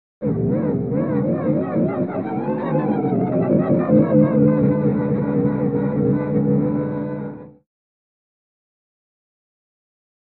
Engine Start; Synthesized Pulsating Engine False Start.